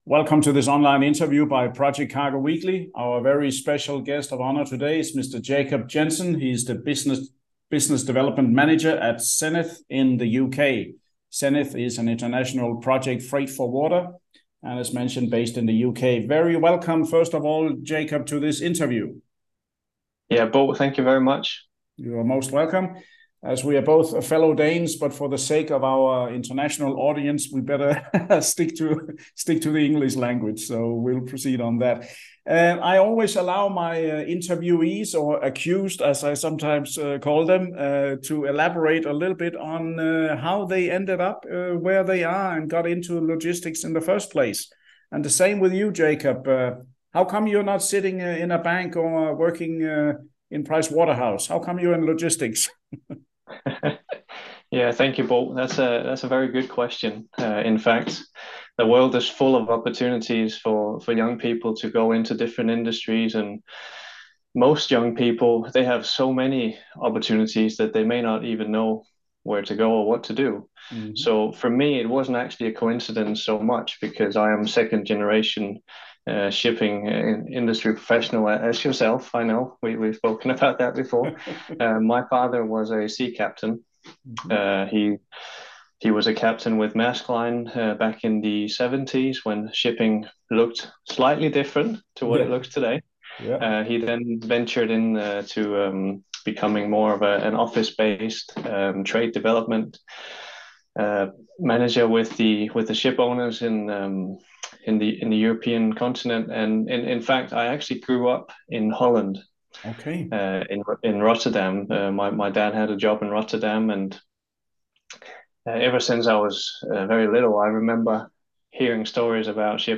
Video InterviewZenith International Freight